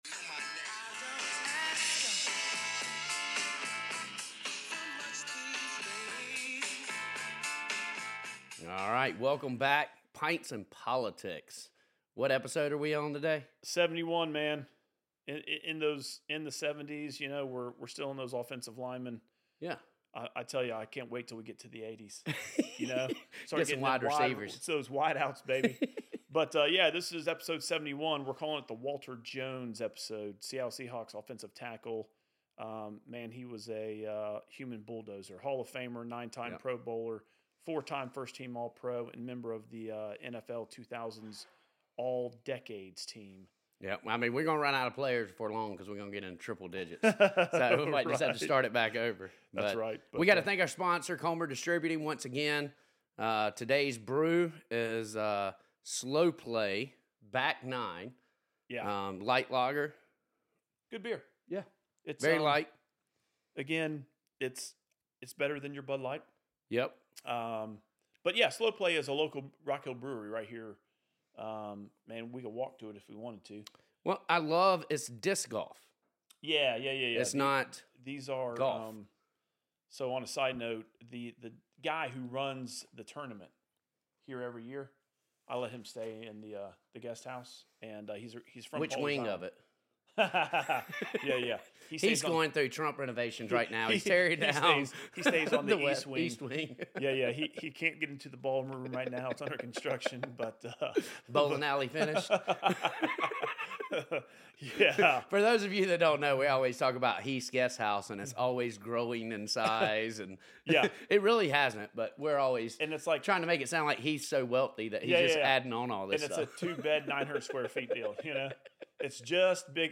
This week SC Reps Brandon Guffey & Heath Sessions discuss an ethical dilemma and catch everyone up on what's been going on the last two weeks. This weeks topics include Legislative night sponsored by our sponsor Comer Distributing, Hemp drinks, fund raising, movie screenings, big tech, healthcare shortages, DJJ and much more.